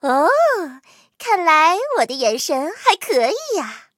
M10狼獾获得资源语音.OGG